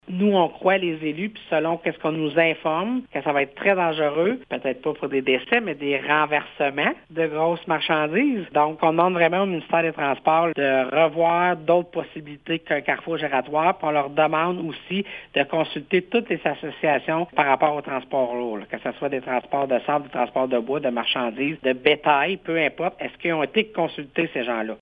La préfète de la Vallée-de-la-Gatineau, Chantal Lamarche fait le point sur la situation :